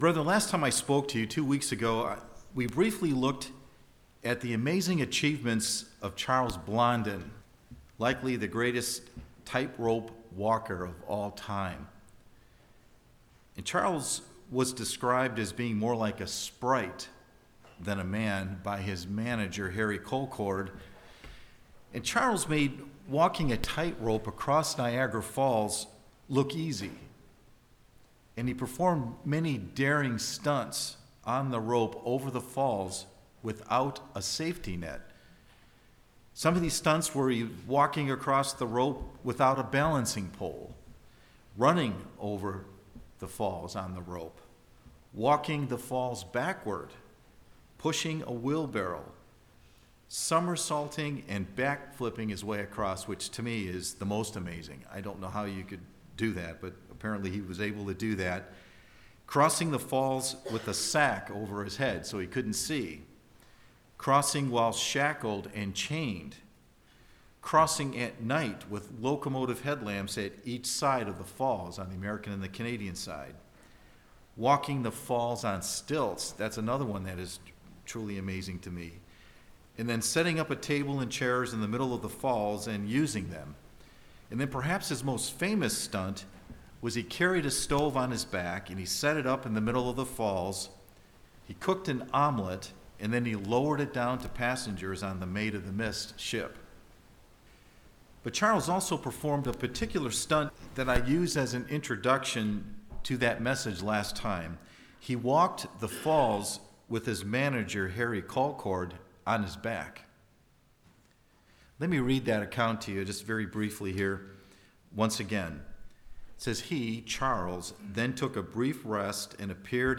We first must believe that God exists and that the physical is not the only thing that exists in order to truly trust God. This Sermon covers the last six elements in trusting God.